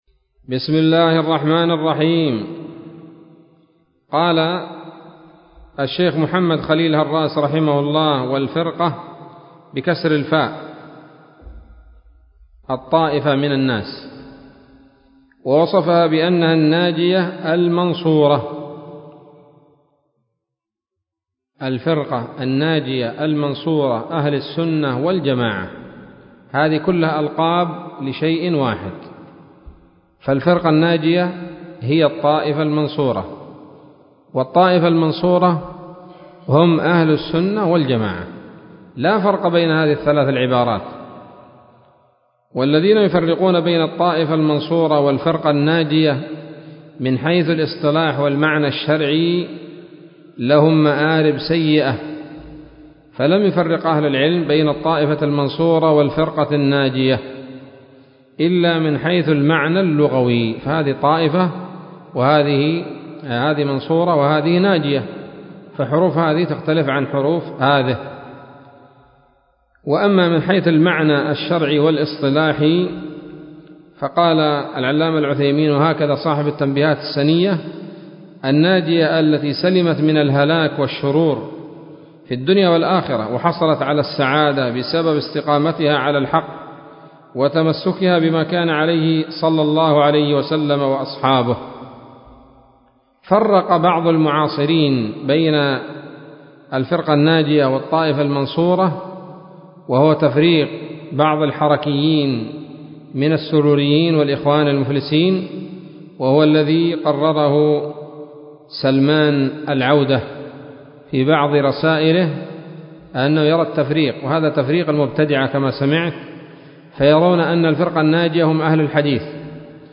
الدرس الثامن عشر من شرح العقيدة الواسطية للهراس